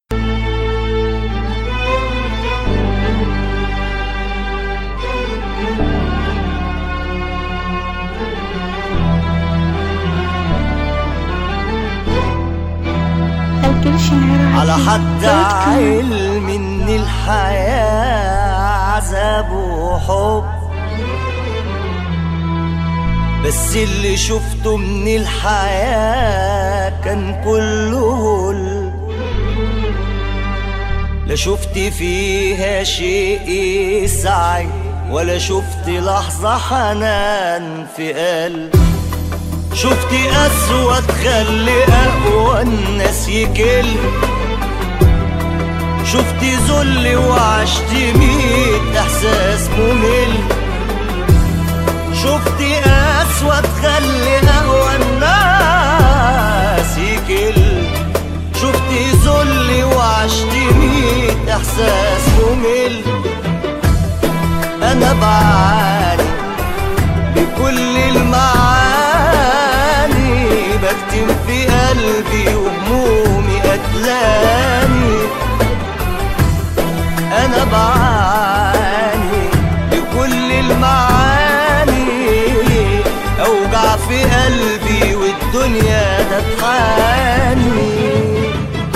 اغاني مصرية حزينة 2017